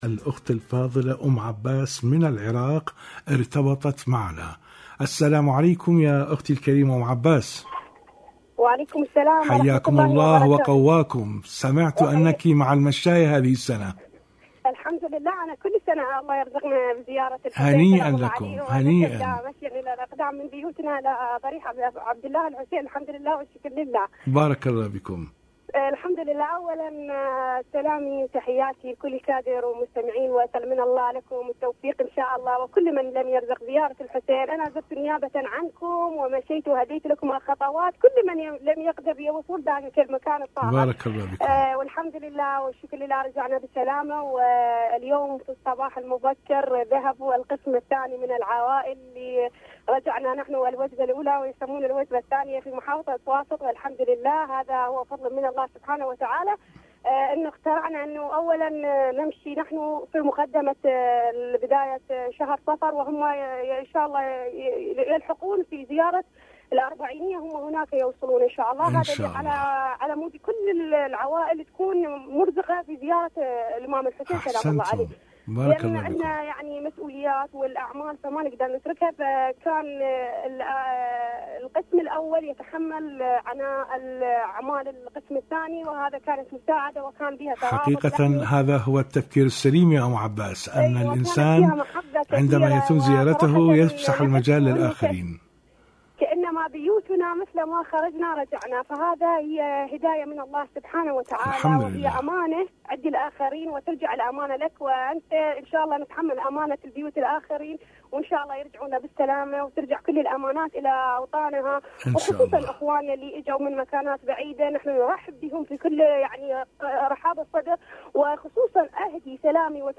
المشاركة الصوتية